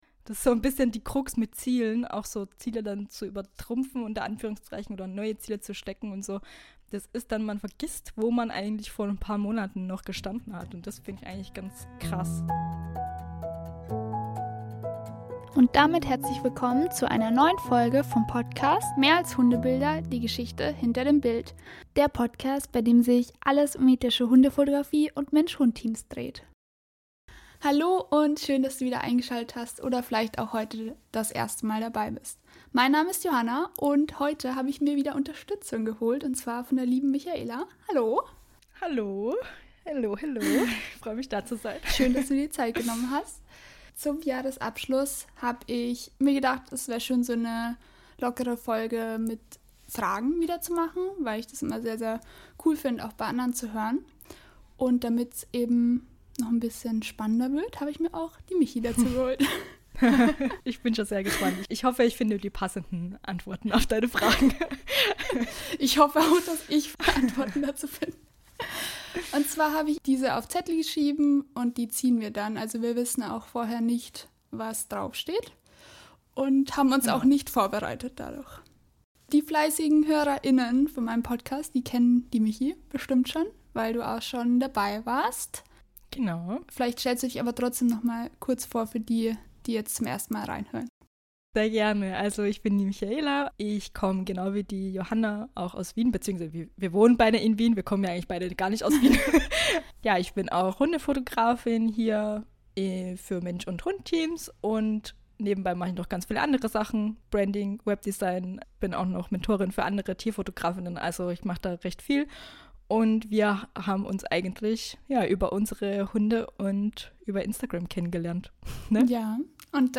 Eine gemütliche Folge unter Freunden & Kolleginnen.